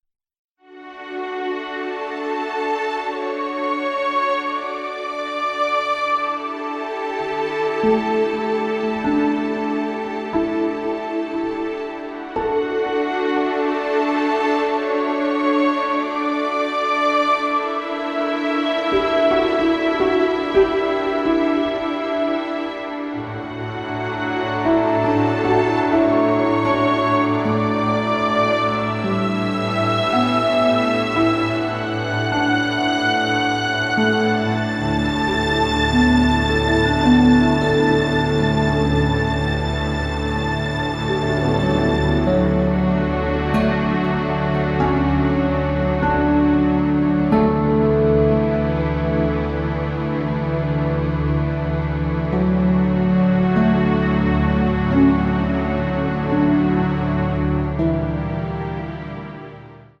Sphärische Musik zum Entspannen und Meditieren